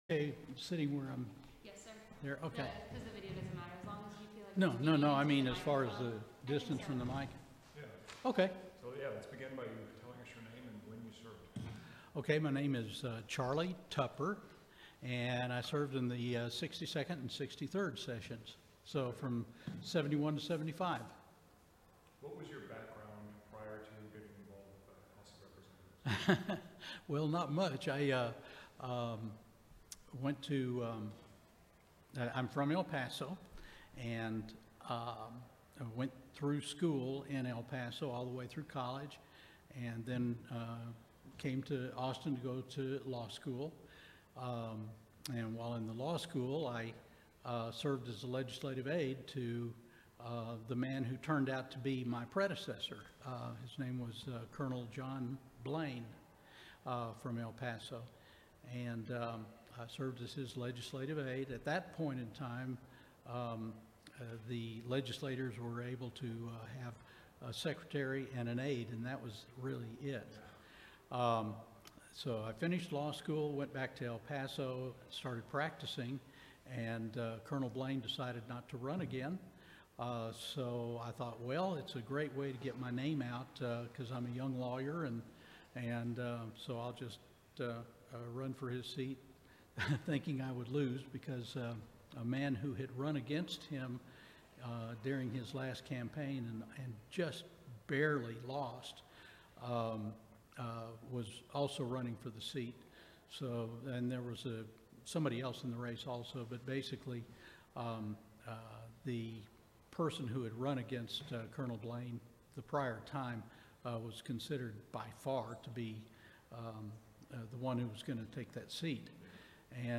Oral history interview with Charles Tupper, 2015.